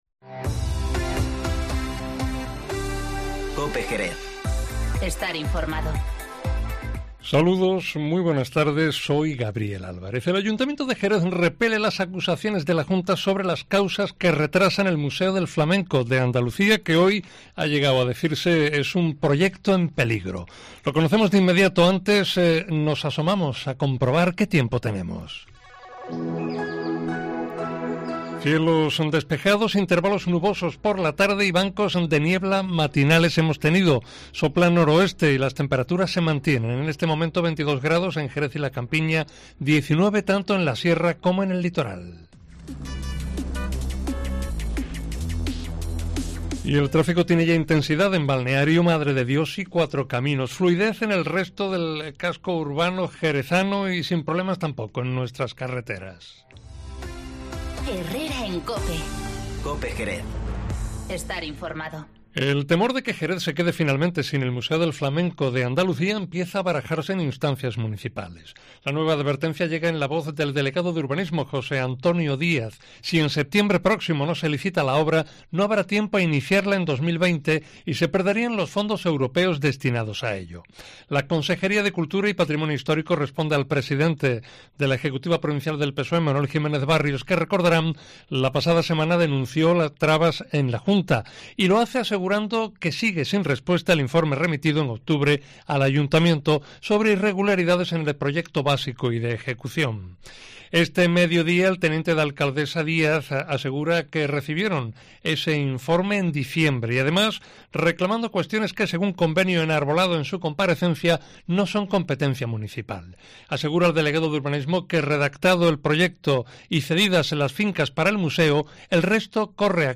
Informativo Mediodía COPE en Jerez 17-02-20